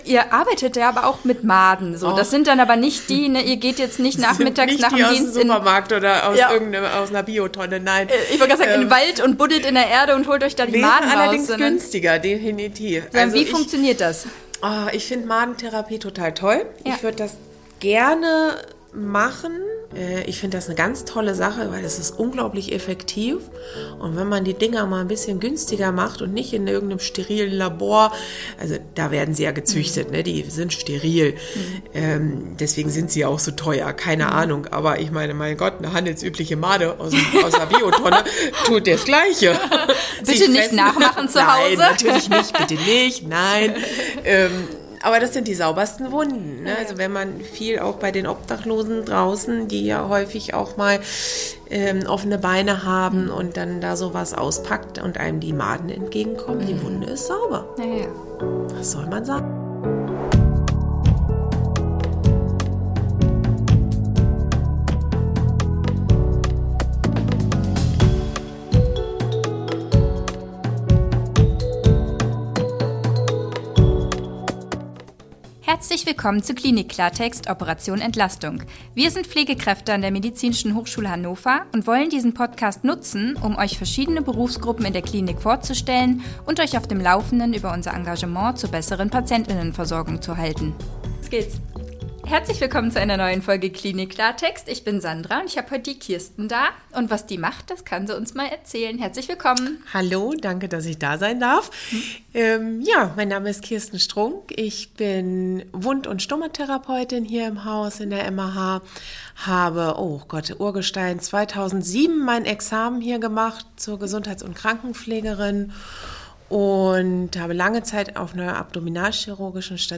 Wund- und Stomatherapie – Ein Interview